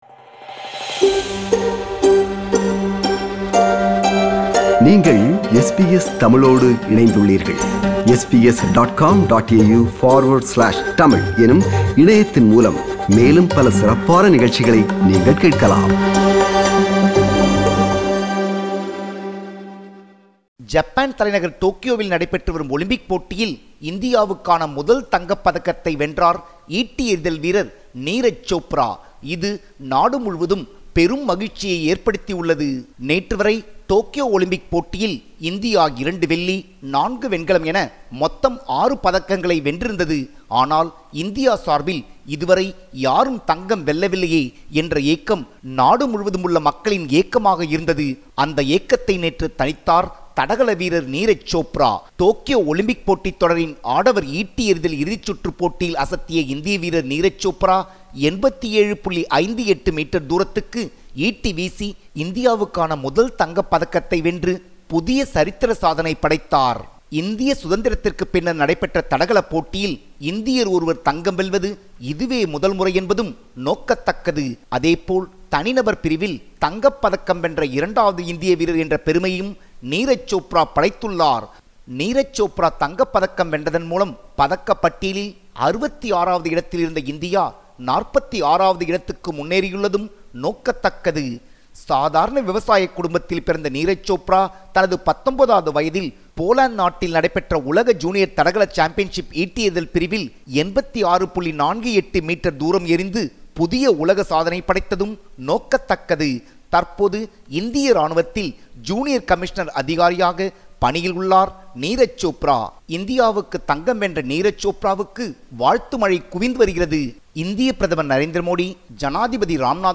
இது குறித்த விவரணத்தை முன்வைக்கிறார் நமது தமிழக செய்தியாளர்